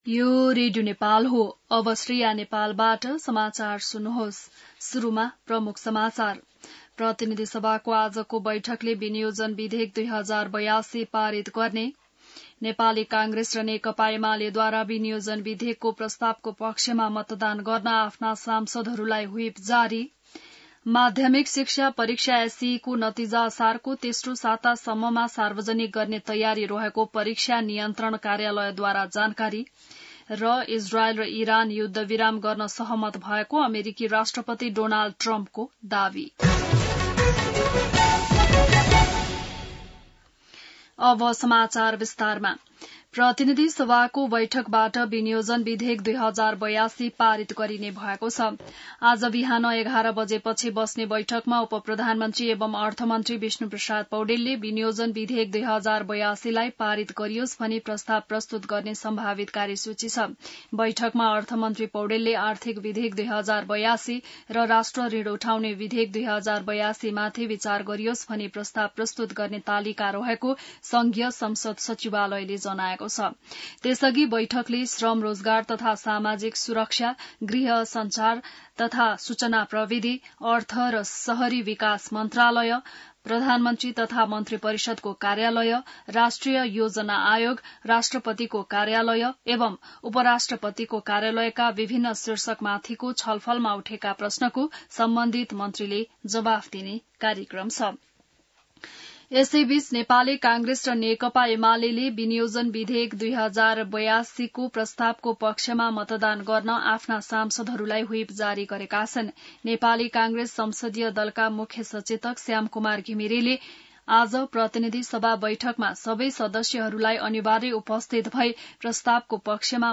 बिहान ९ बजेको नेपाली समाचार : १० असार , २०८२